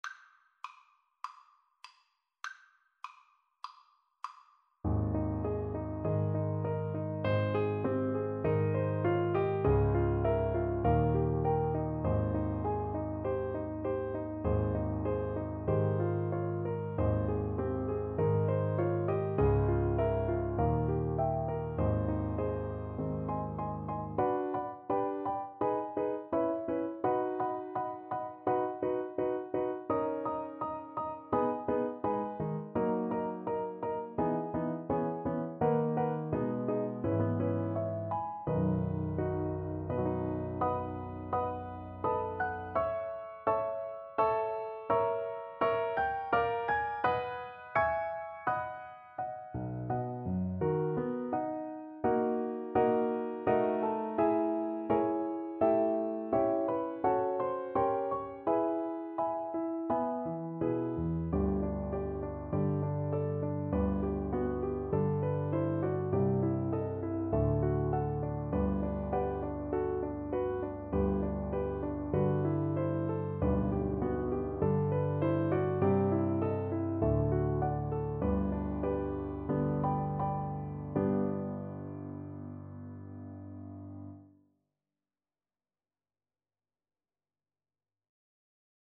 Play (or use space bar on your keyboard) Pause Music Playalong - Piano Accompaniment Playalong Band Accompaniment not yet available reset tempo print settings full screen
Moderato
Ab major (Sounding Pitch) (View more Ab major Music for Viola )
Classical (View more Classical Viola Music)